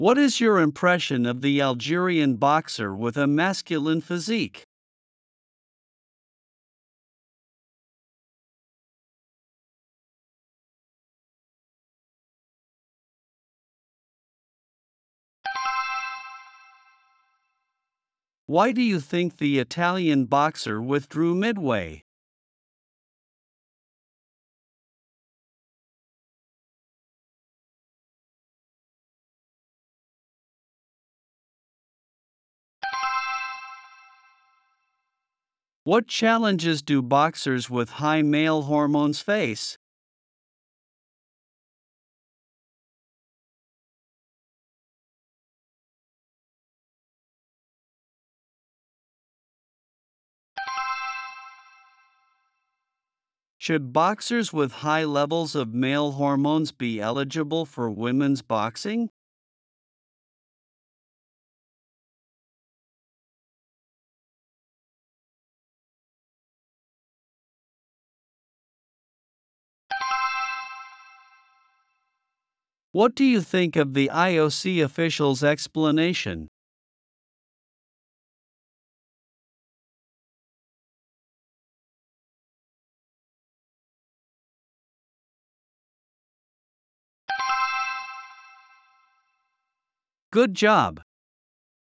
プレイヤーを再生すると英語で5つの質問が1問ずつ流れ、10秒のポーズ（無音部分）があります。
10秒後に流れる電子音が終了の合図です。
10秒スピーチ質問音声